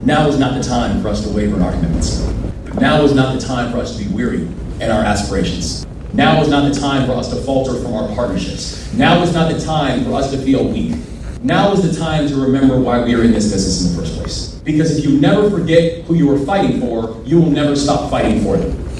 The Appalachian Regional Commission’s 13 states came together at Frostburg State University for their annual conference this week.  Maryland Governor Wes Moore who is serving as co-chair along with Gayle Manchin, spoke to attendees, giving them encouragement to continue their work, despite program funding not being finalized due to federal government delays…